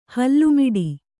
♪ hallu miḍi